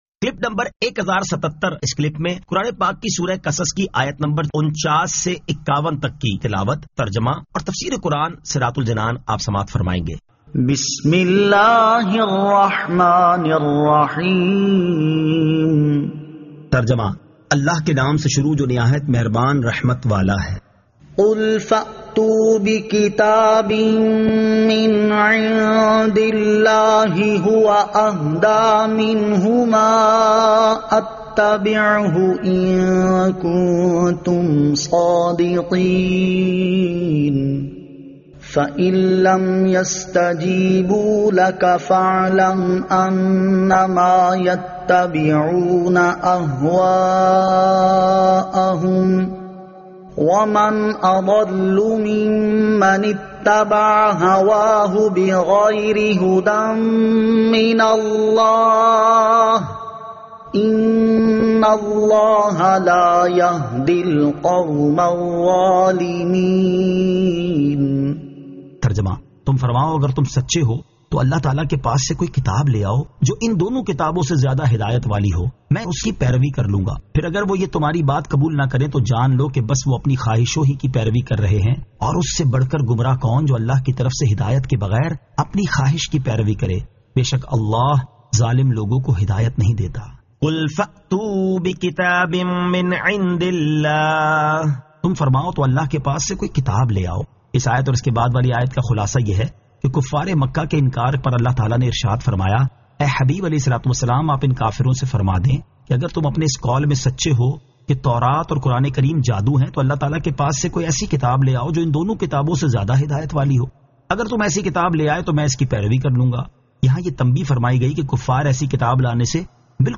Surah Al-Qasas 49 To 51 Tilawat , Tarjama , Tafseer